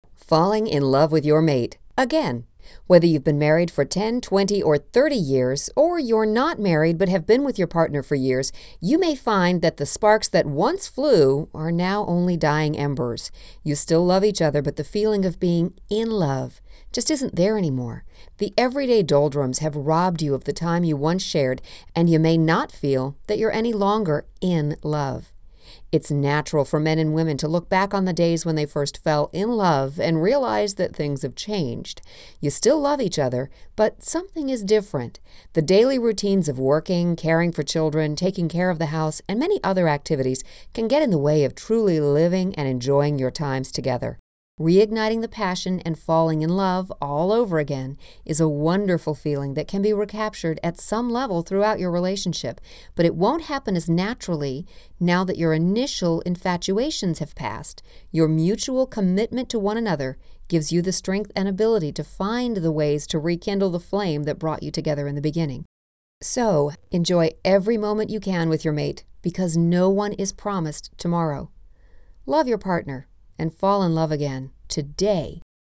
How To Fall In Love With Your Mate Again Audio Book